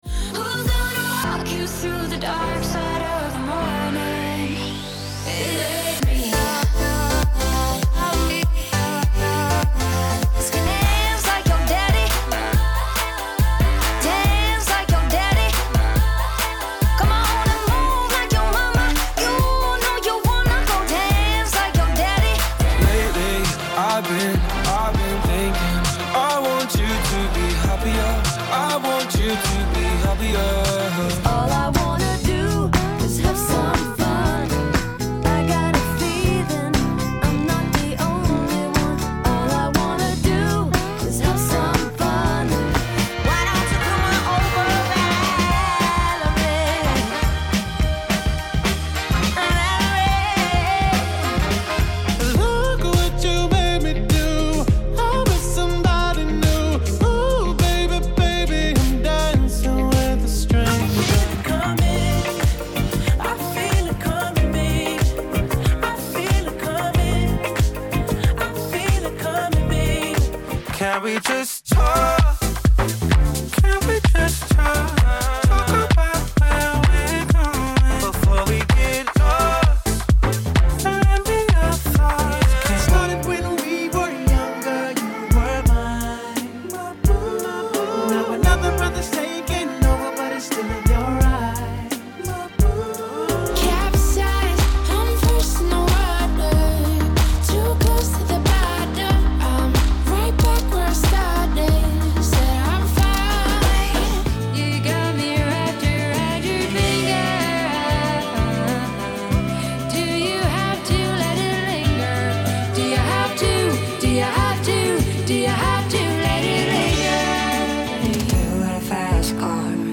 Mixed Tempo